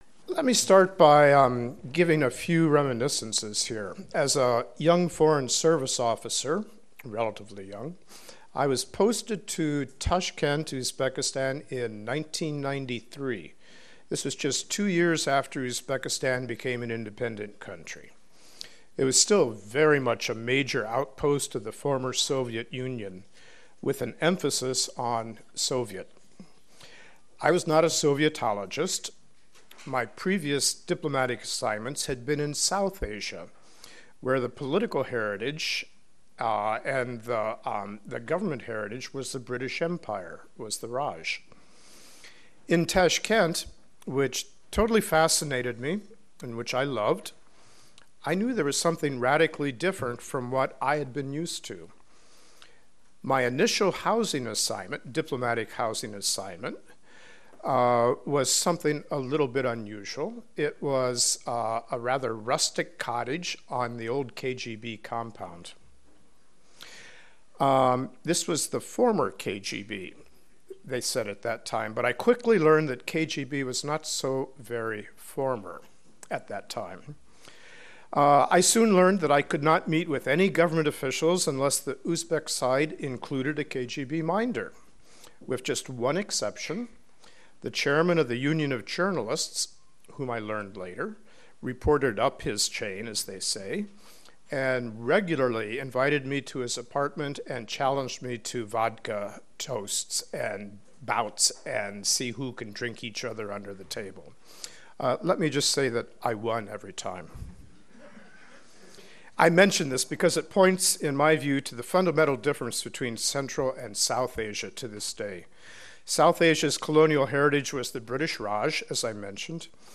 Remarks by Richard E. Hoagland, Principal Deputy Assistant Secretary, Bureau of South and Central Asian Affairs, given at Georgetown University, Washington, DC, March 30, 2015.